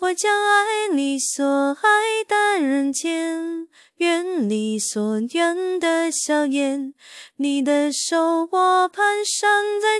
sing_female_10s.wav